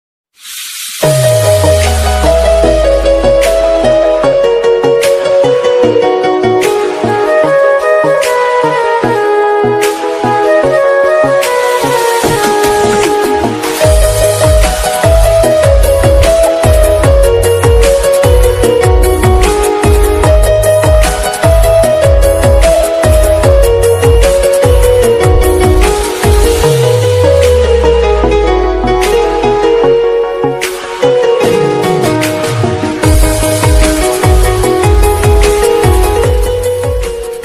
Category: BGM